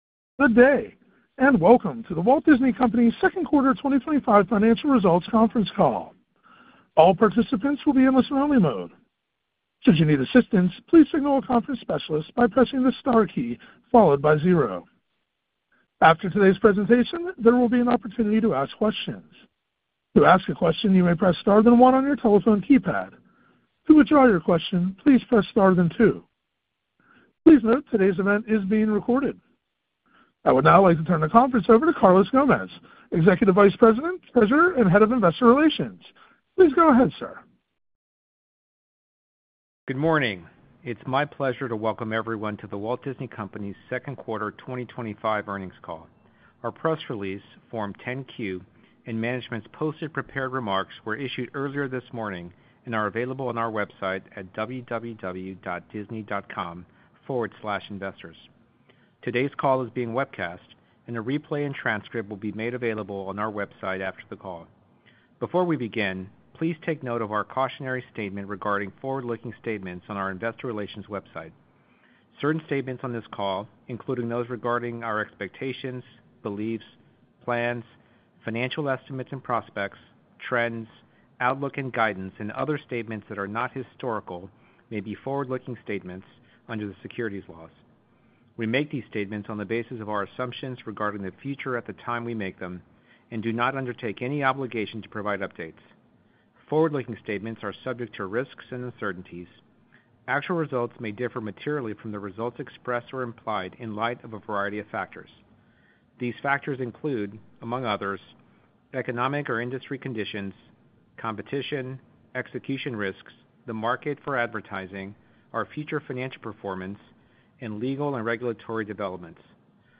The Walt Disney Company hosted a live audio webcast to discuss fiscal second quarter 2025 financial results on Wednesday, May 7, 2025.